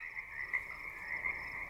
crickets.wav